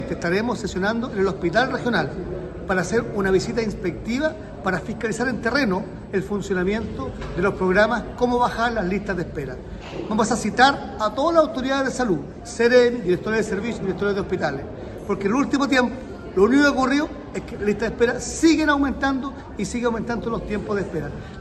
Así lo dijo el miembro de la comisión y diputado del distrito 23, el UDI Henry Leal.